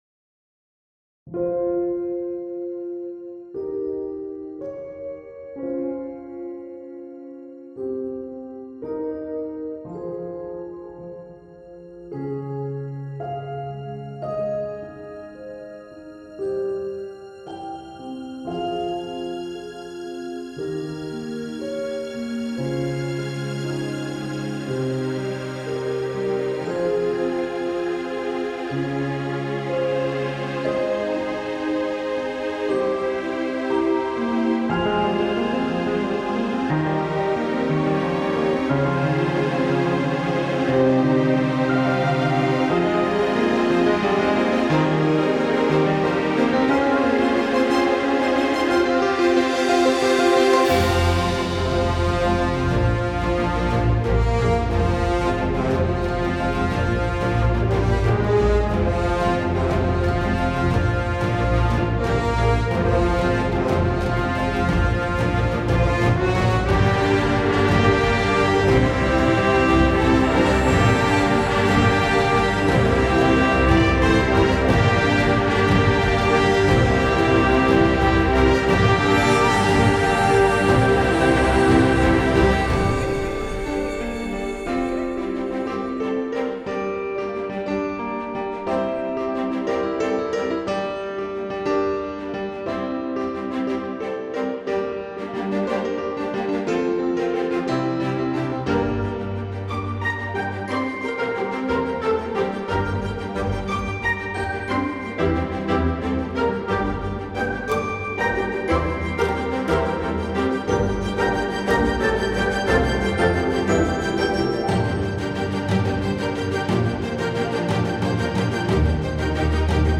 Кинематографичная музыка без прав